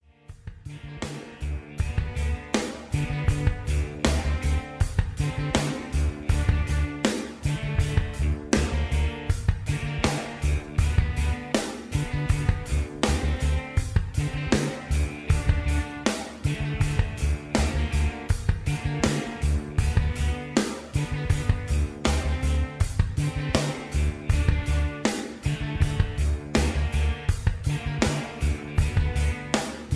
Tags: karaoke, backing tracks, rock